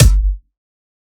Kick 4 (Scary bolly dub).wav